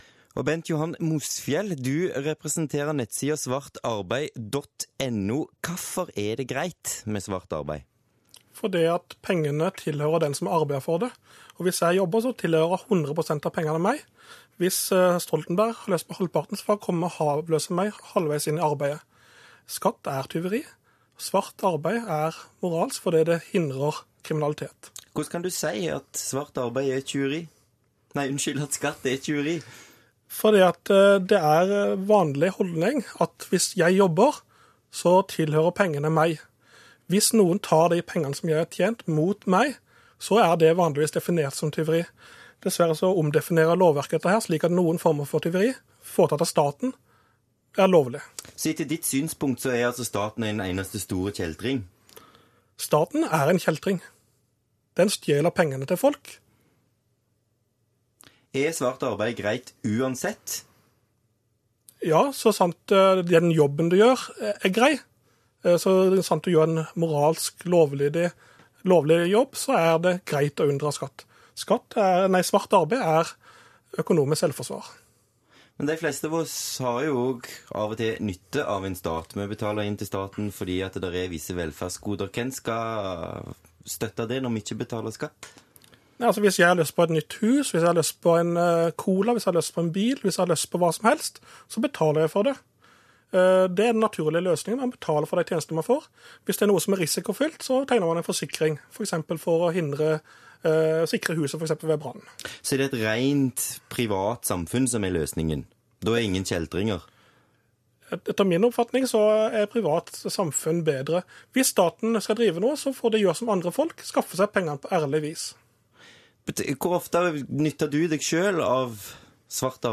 Forut for intervjuet var det diverse innspill fra "folk i gata" og et fire minutters intervju med en dame som jobbet i skattedirektoratet.